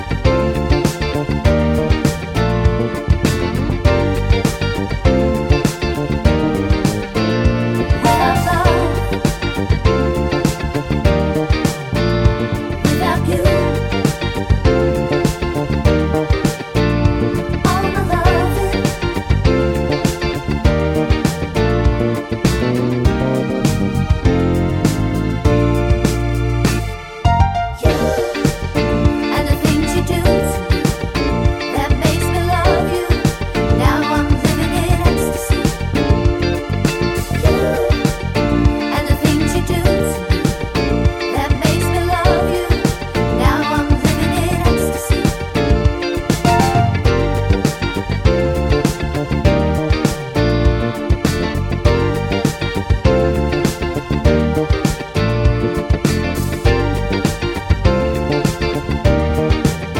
Medley Disco